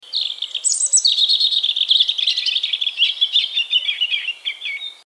Das Rotkehlchen
Rotkehlchen_audio.mp3